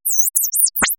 debug audio file to use to test the correctness of my renders.